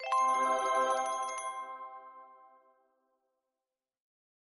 Darmowe dzwonki - kategoria SMS
Dzwonek - Wygrana